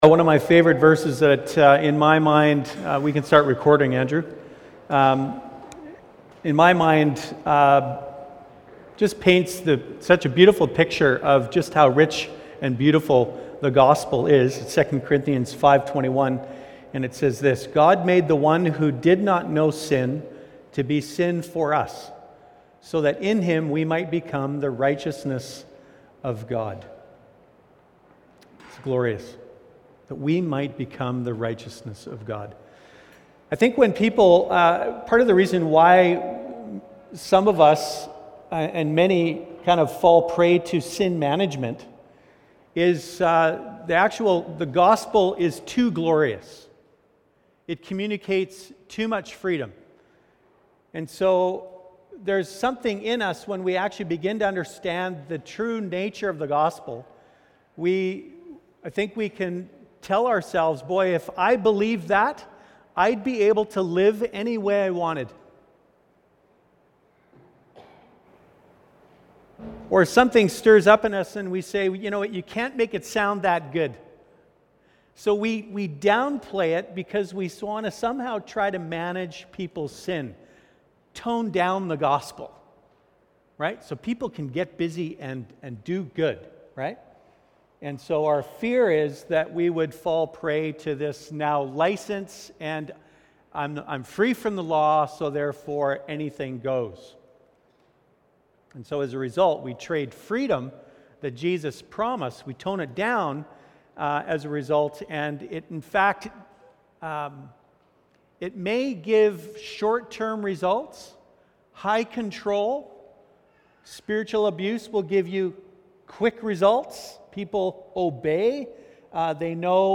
Sermons | The River Church
There are a few group discussions intermingled within the message, which adds to the length.